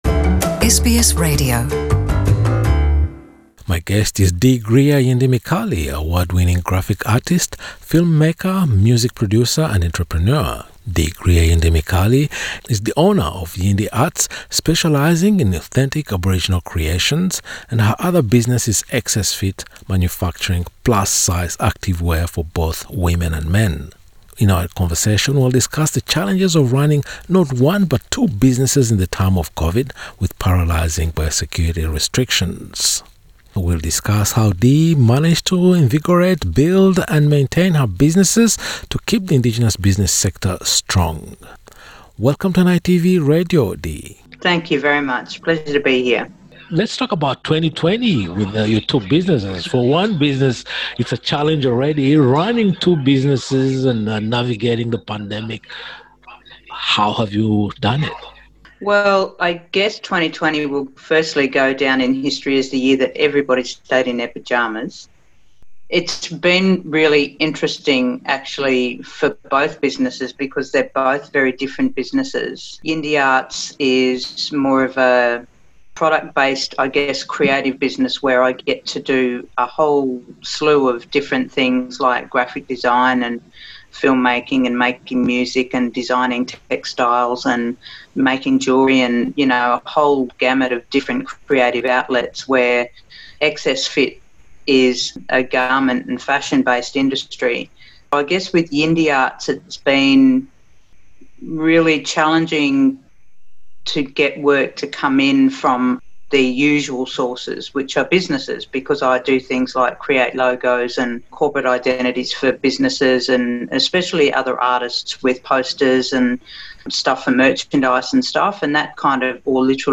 In a conversation with NITV Radio, she explained the differences between her two businesses and the distinct paths undertaken to steer them through the pandemic.